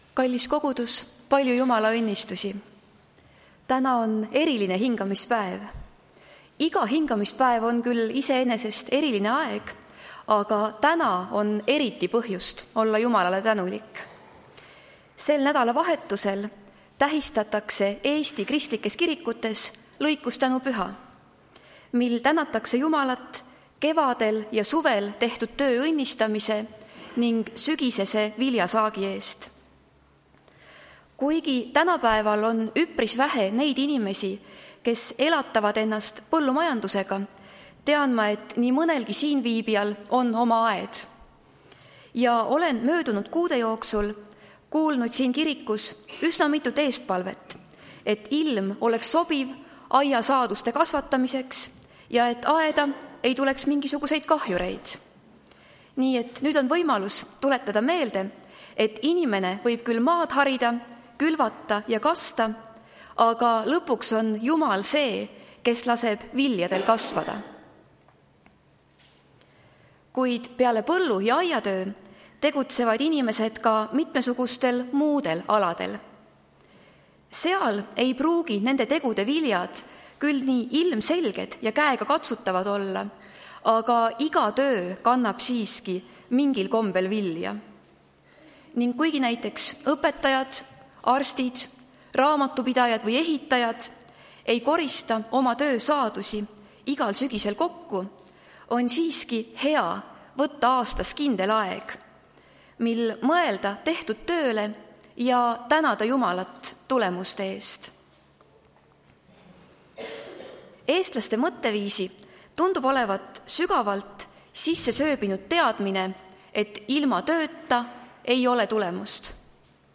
Tartu adventkoguduse 11.10.2025 teenistuse jutluse helisalvestis.
Jutlused